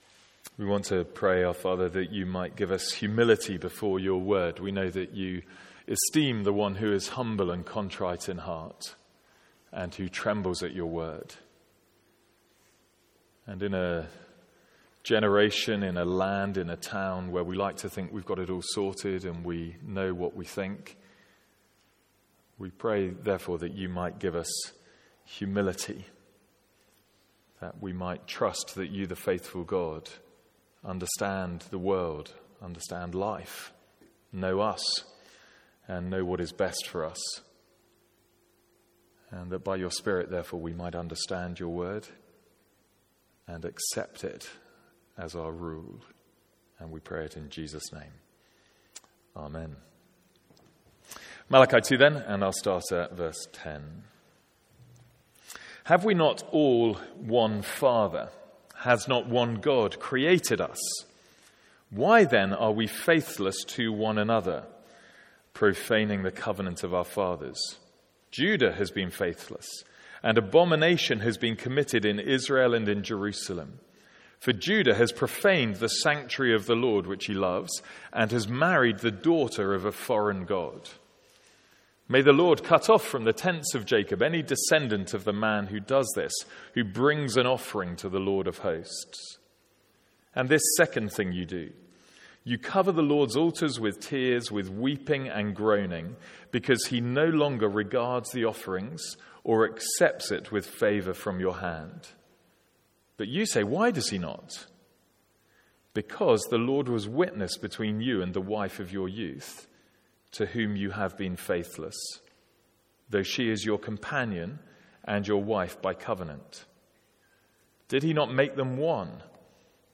Sermons | St Andrews Free Church
From our evening service in Malachi.